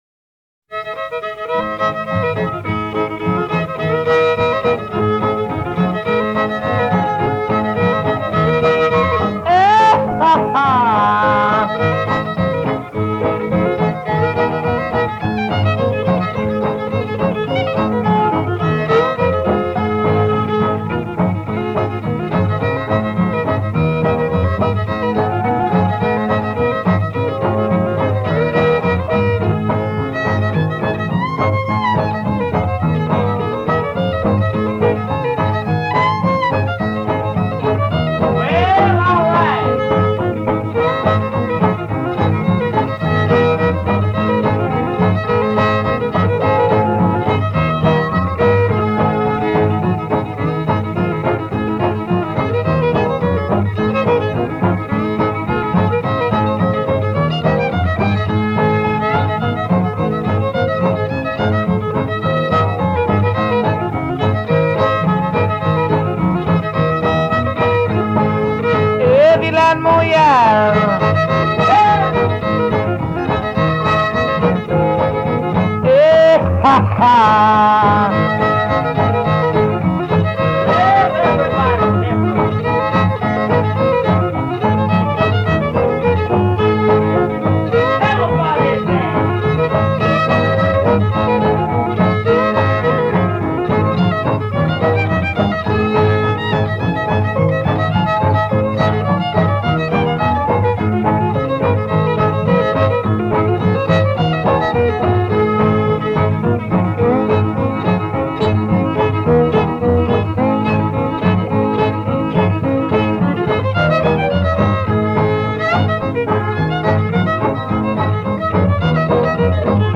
Немного кантри